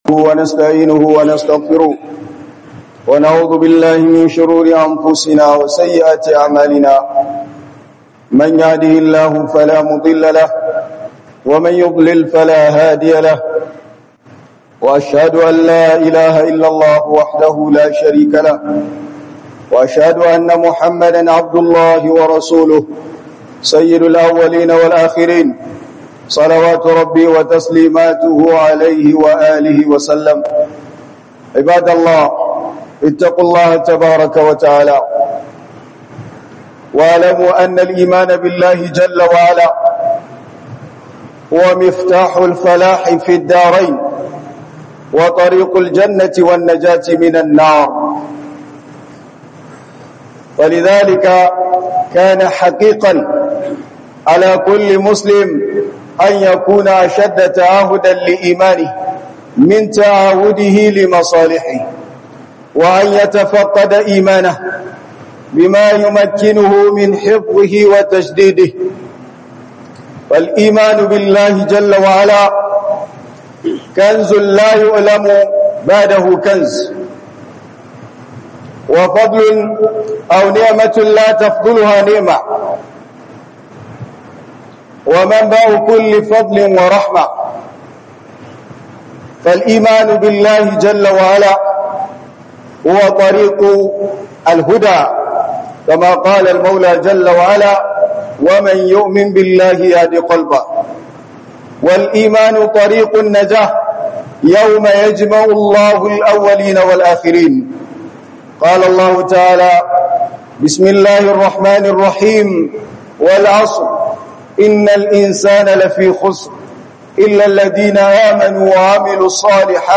hudubar juma'a 15 Août 2025